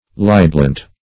Search Result for " libelant" : The Collaborative International Dictionary of English v.0.48: Libelant \Li"bel*ant\ (-ant), n. One who libels; one who institutes a suit in an ecclesiastical or admiralty court.
libelant.mp3